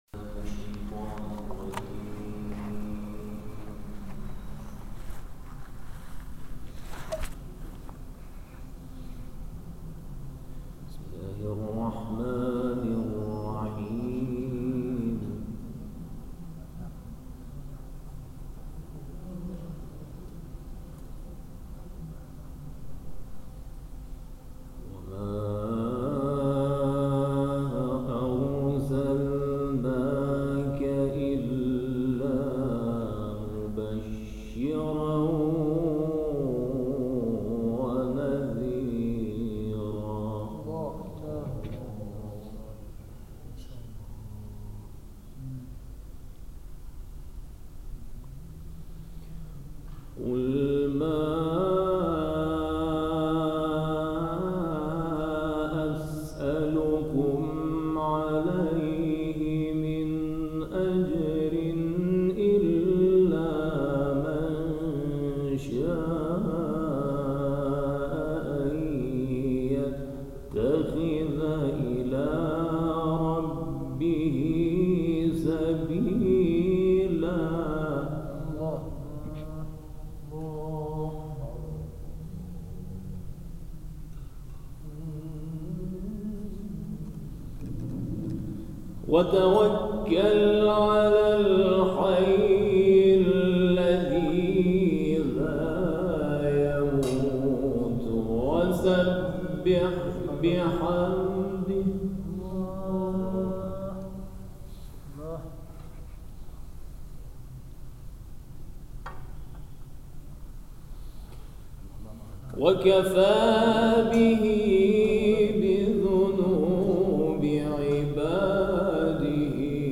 یکسان بودن حروف و حرکات رعایت نشد و تلاوت، یکپارچه نبود.
در ادامه تلاوت‌های این جلسه ارائه می‌شود.